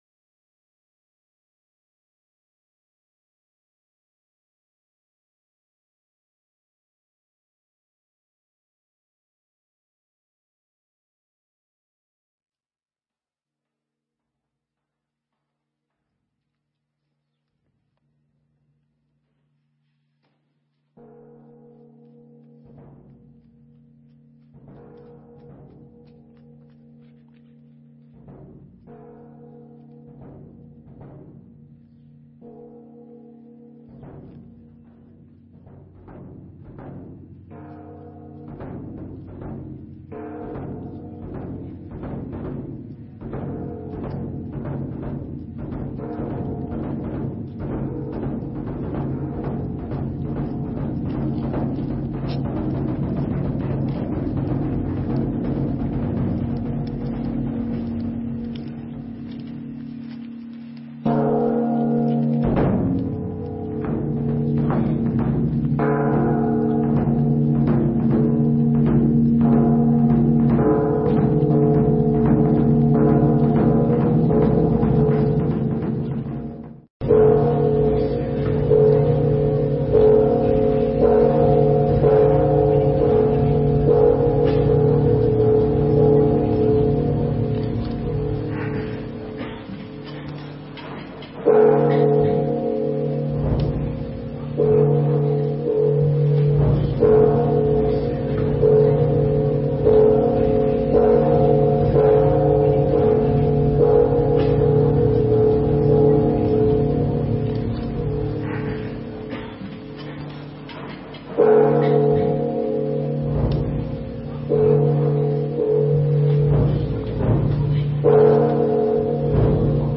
Mp3 Pháp Thoại Lý Thuyết Và Thực Hành – Thầy Thích Thanh Từ giảng tại Thiền Viện Trúc Lâm Phụng Hoàng, ngày 17 tháng 8 năm 2006 (ngày 24 tháng 7 năm Bính Tuất)